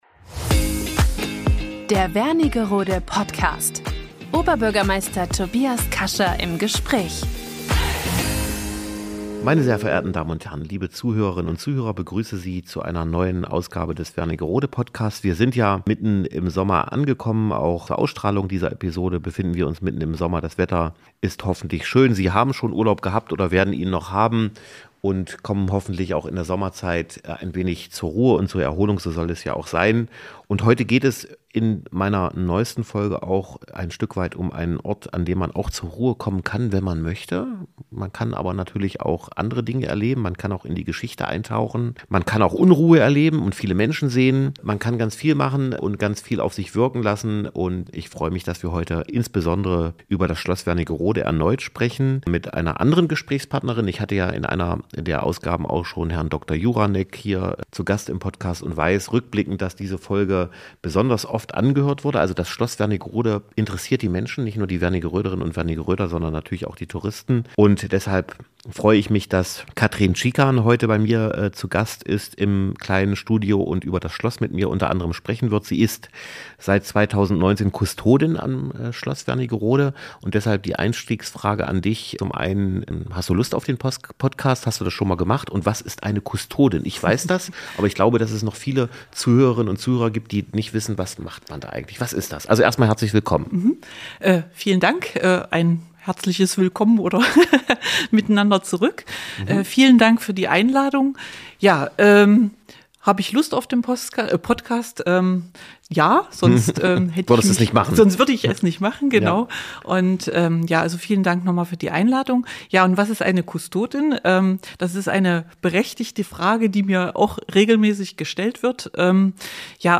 Wernigerode Podcast #47 - Oberbürgermeister Tobias Kascha im Gespräch